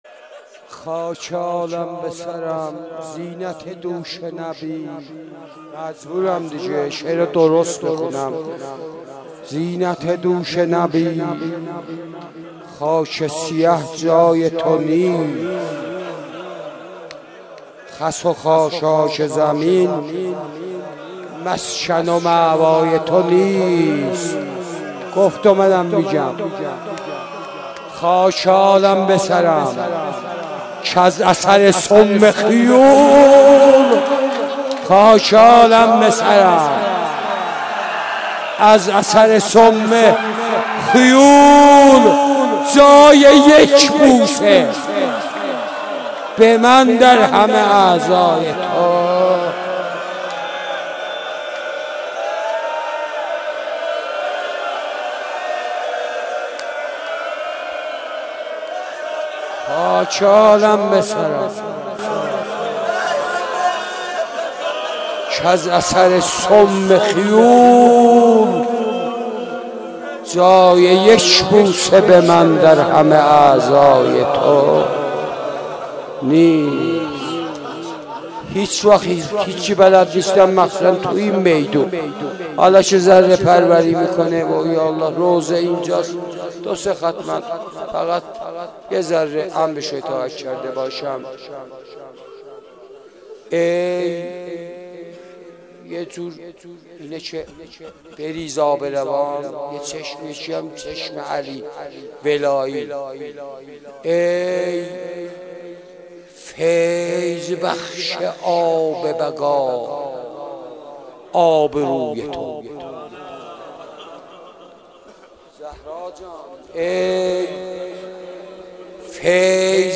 مناسبت : شب بیست و پنجم رمضان
قالب : روضه